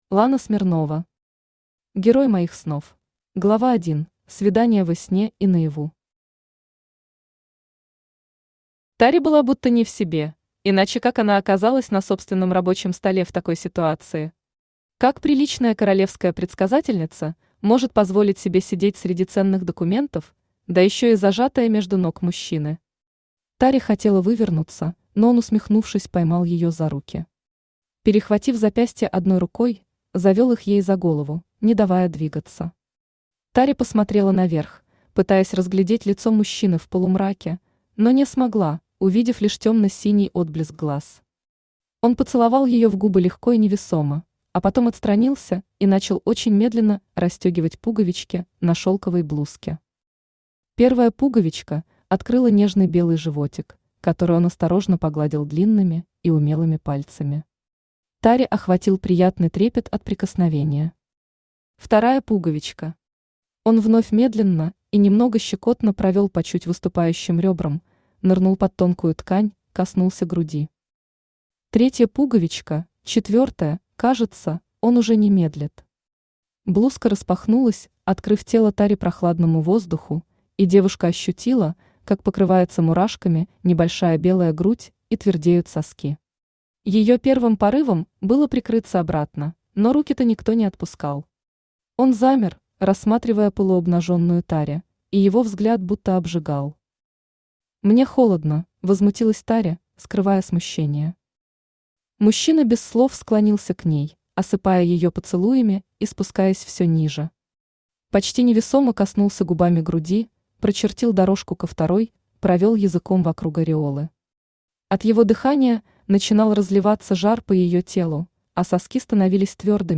Аудиокнига Герой моих снов | Библиотека аудиокниг
Aудиокнига Герой моих снов Автор Лана Смирнова Читает аудиокнигу Авточтец ЛитРес.